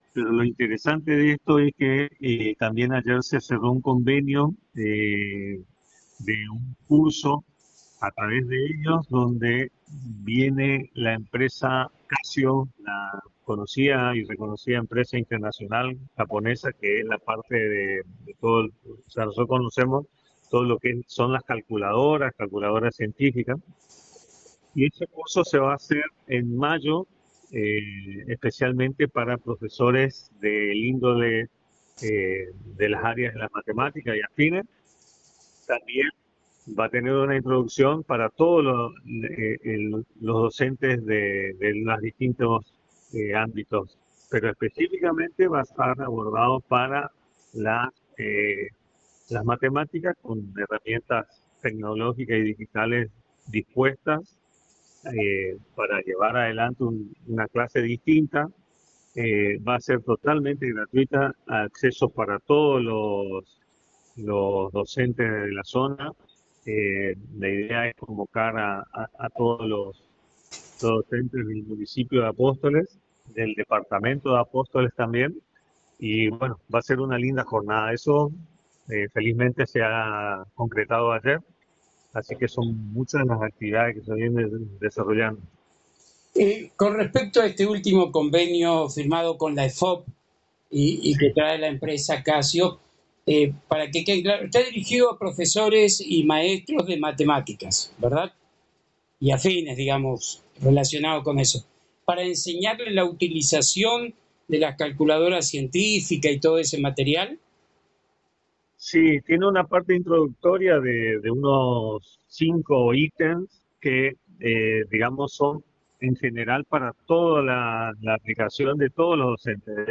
en diálogo exclusivo con la ANG a través del Programa «La Buena Noticia»